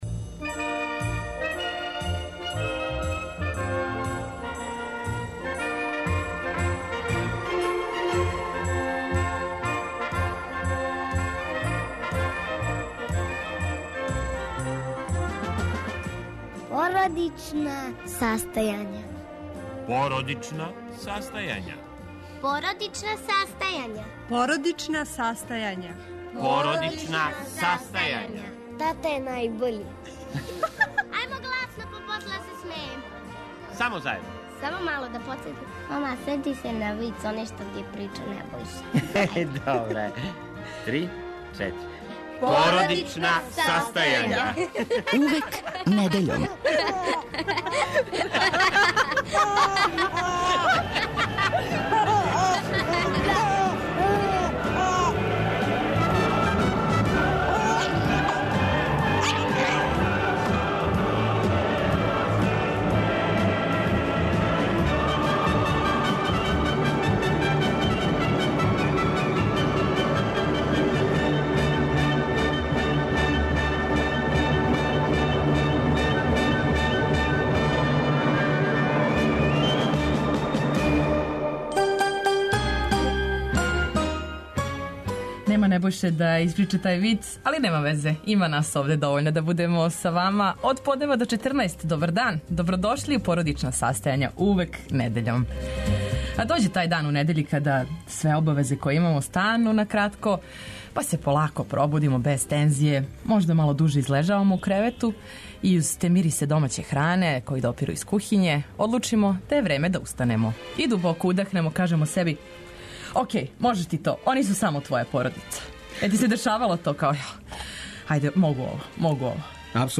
Квиз "Радио геније" идеална је прилика да проверите јесте ли коначно паметнији од тате.